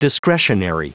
Prononciation du mot discretionary en anglais (fichier audio)
Prononciation du mot : discretionary